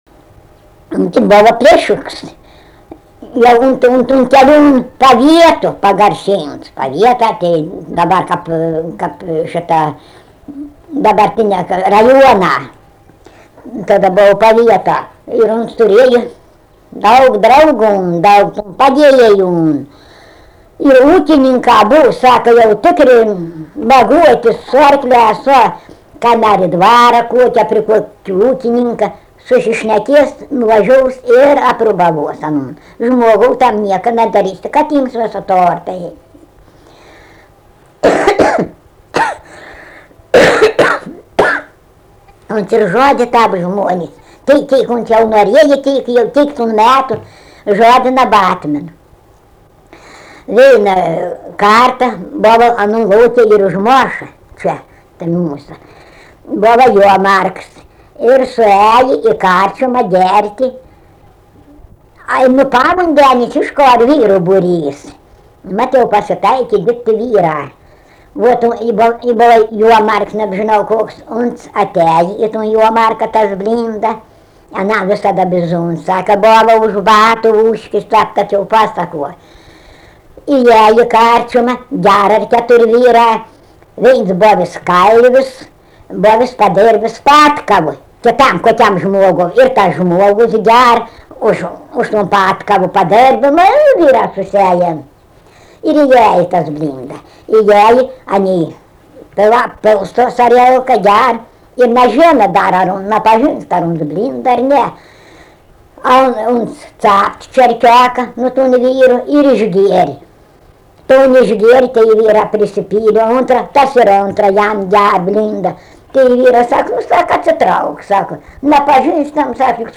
Dalykas, tema daina
Erdvinė aprėptis Barvydžiai
Atlikimo pubūdis vokalinis